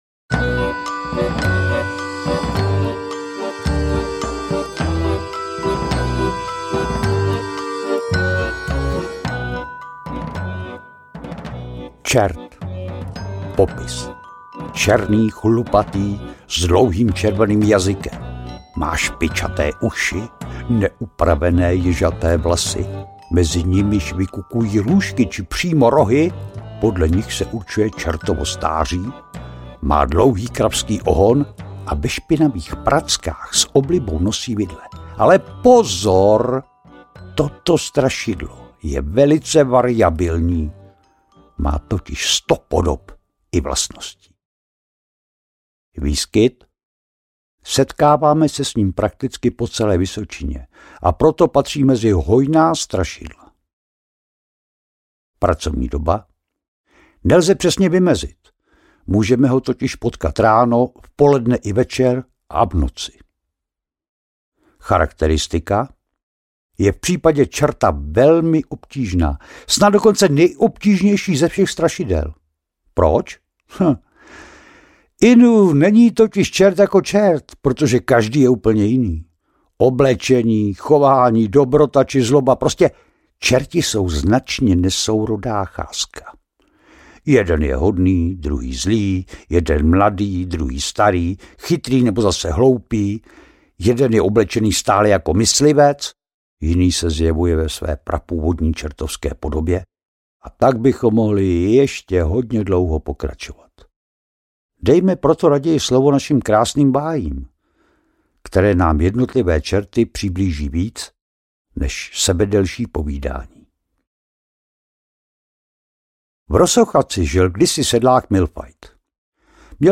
Tajemný svět strašidel audiokniha
Ukázka z knihy
výrazný a záhadný hlas